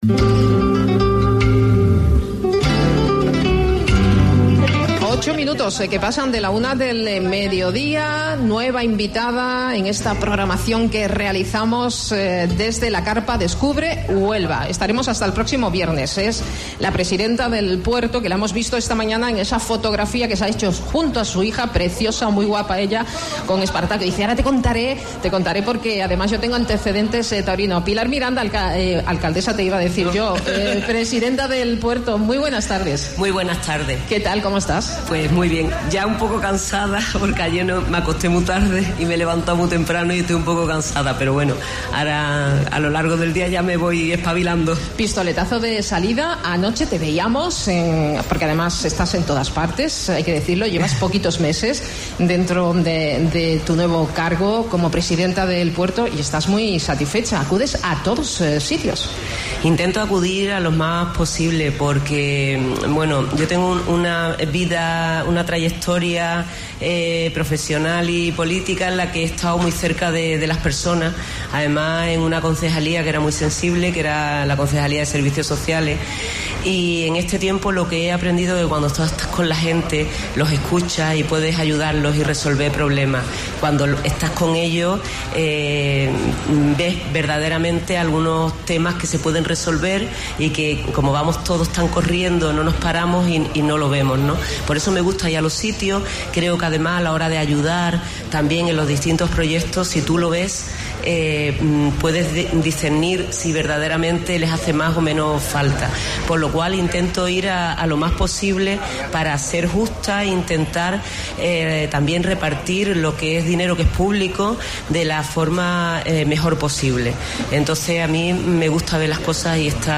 AUDIO: Presidenta del Puerto desde carpa Descubre Huelva en Herrera en Cope